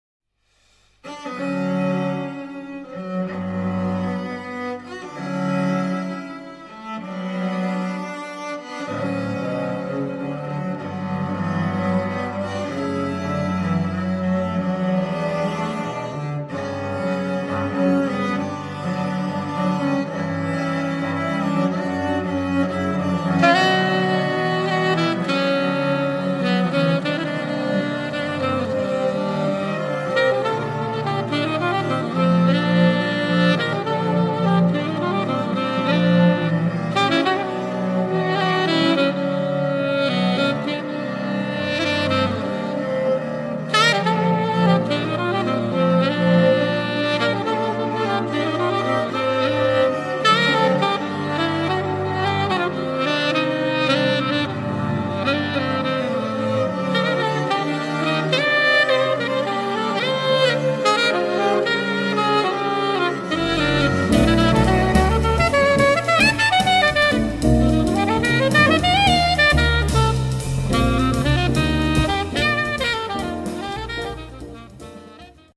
sax contralto/clarinetto
violoncello
chitarre
contrabbasso
batteria